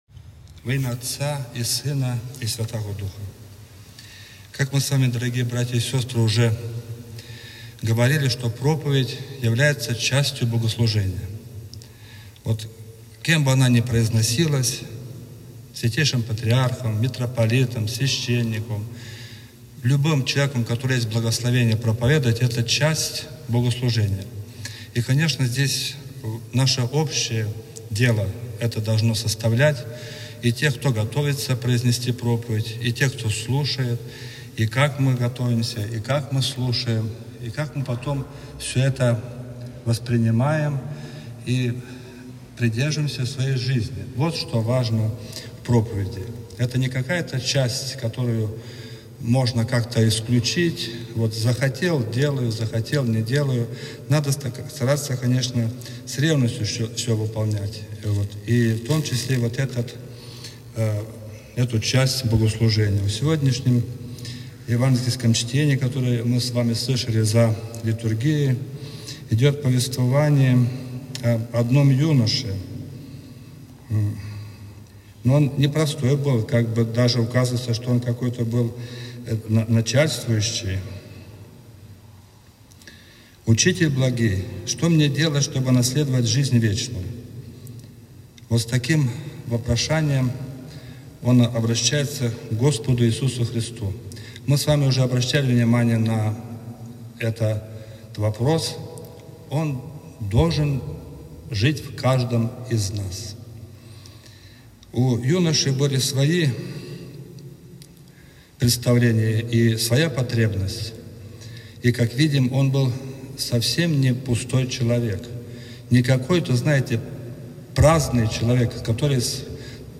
Проповедь-3.mp3